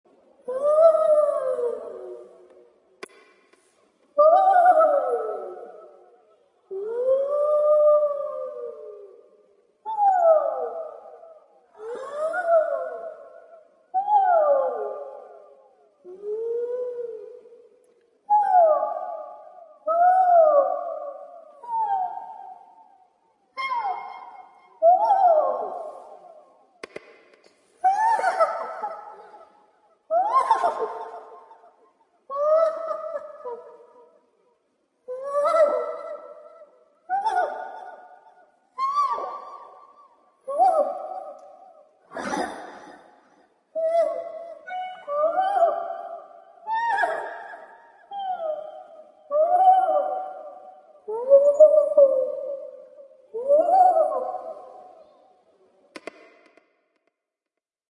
Download Funny Screams sound effect for free.
Funny Screams